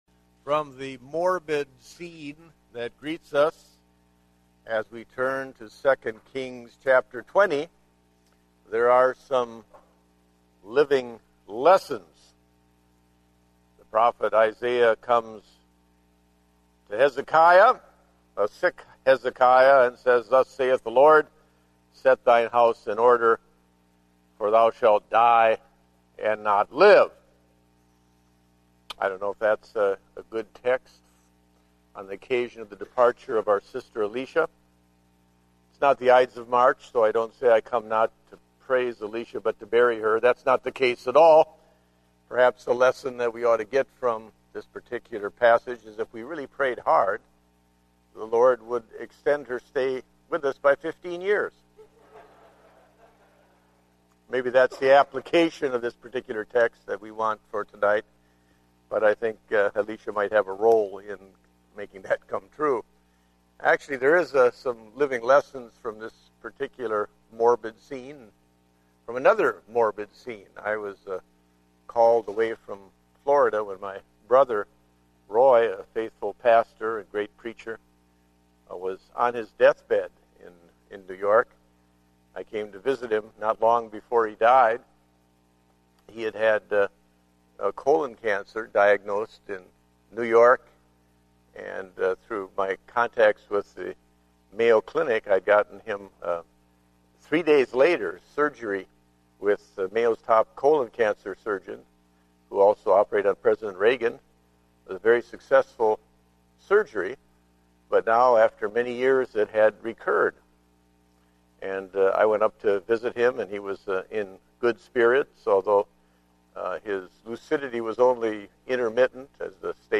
Date: May 23, 2010 (Evening Service)